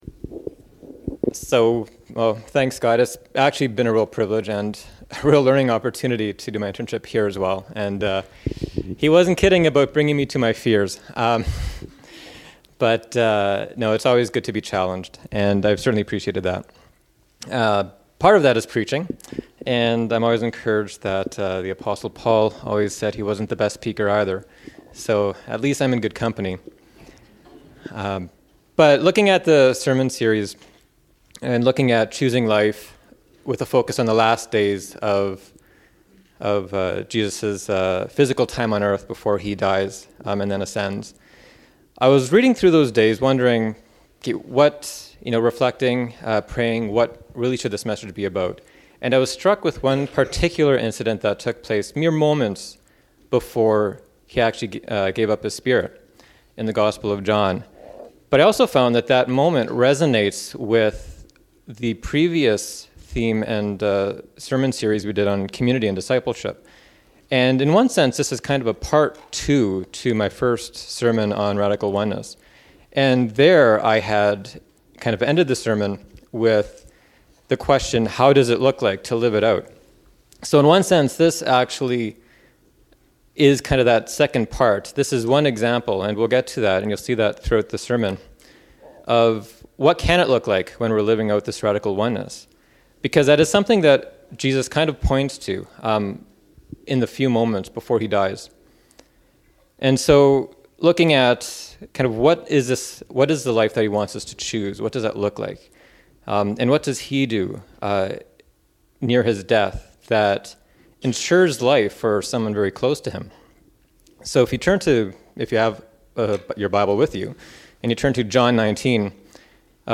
Choose Life Service Type: Sunday Morning Preacher